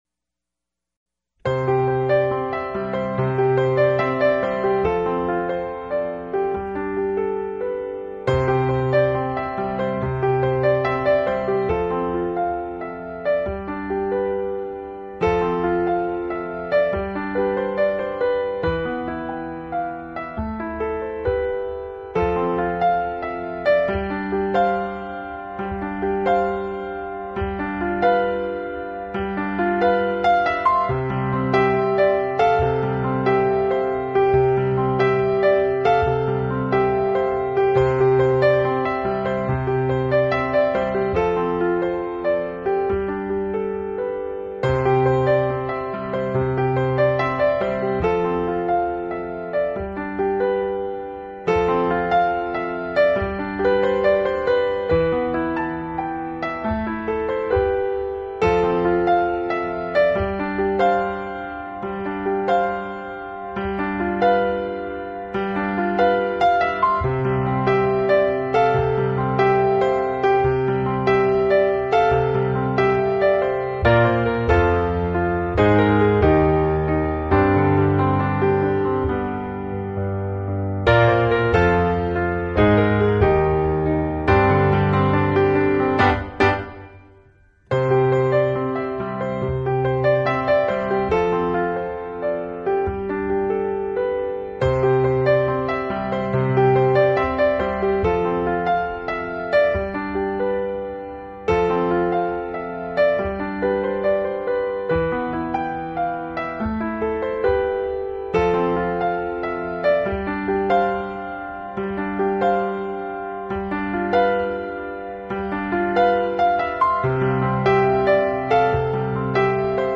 音乐类型：New Age
专辑独特的素净气氛中，品尝钢琴不染尘俗的真正魅力。
淡淡的琴声 淡淡的忧伤  尘封的记忆是否也被轻轻地开启了呢....